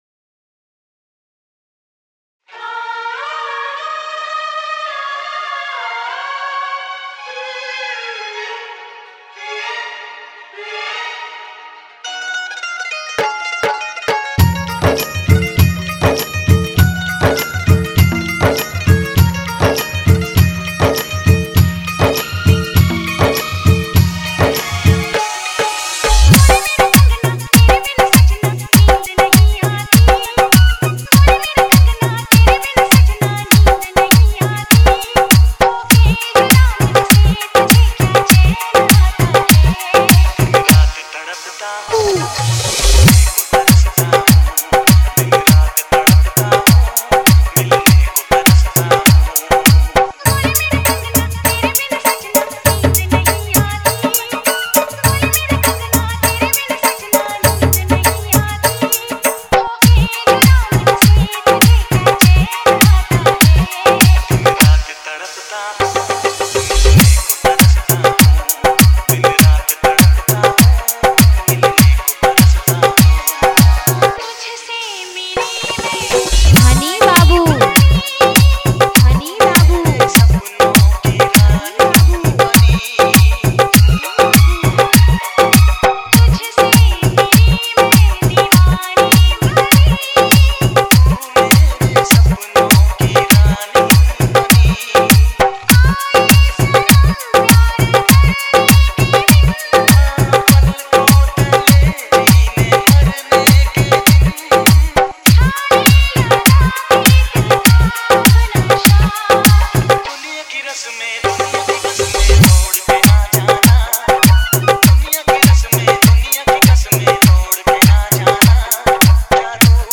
Hindi Dj Songs